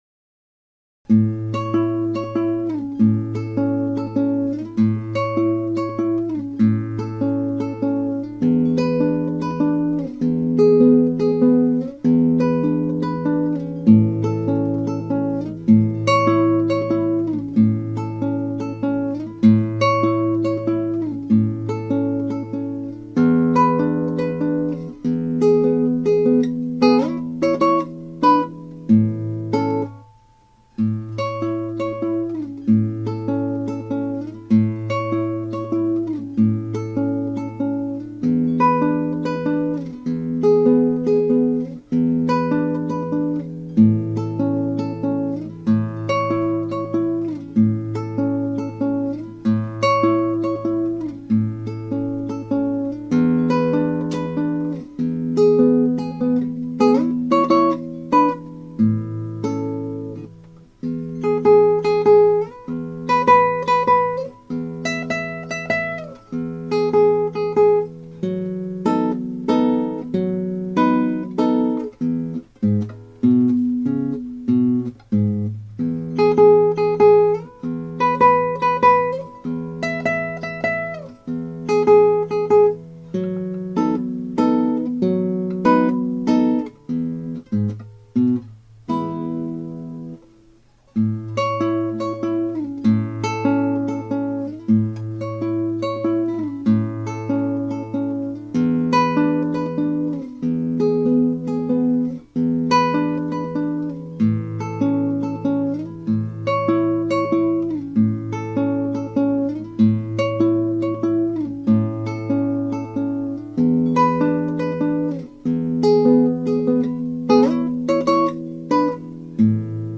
(アマチュアのクラシックギター演奏です [Guitar amatuer play] )
全体にインテンポを重視しました。
ロンドとして繰りかえされるメロディーの装飾音、例えば1小節の2拍、3拍についた装飾音はそれぞれ1拍裏、2拍裏で弾いています。
つまり2拍の装飾音、2拍目、アラストレの2拍裏の3音を3連符のように弾いています。
55小節の頭は楽譜によりフェルマータがつけられていますが私は付けずに弾きました。
装飾音で雨だれとそのしずくを表現した美しい曲です。